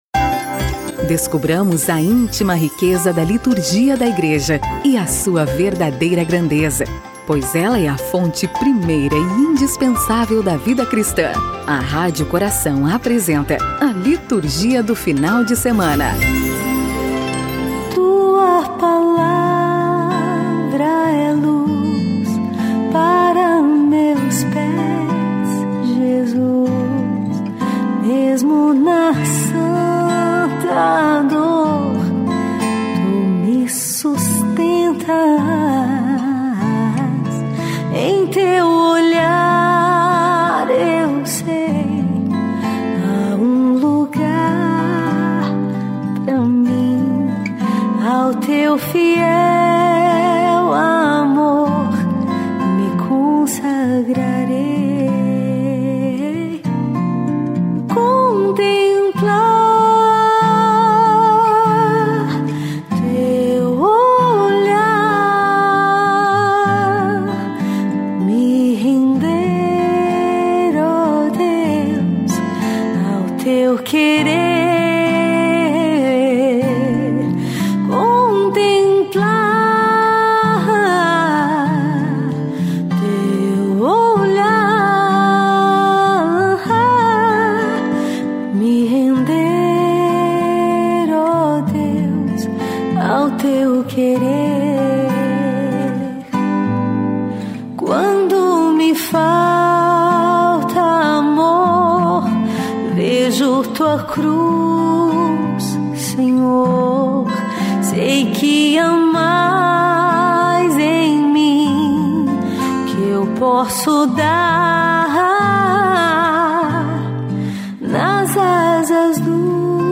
Dom Henrique apresentou nesta sexta (20/04) seu programa semanal, 'A Palavra do Pastor'.
Ele também realizou a leitura da mensagem dos bispos ao povo de Deus.